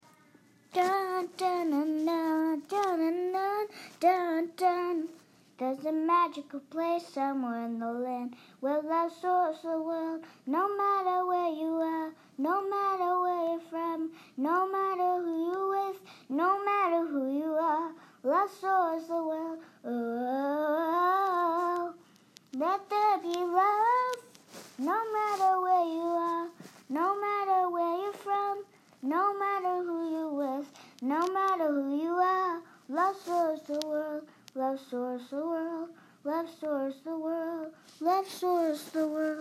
original vocal demo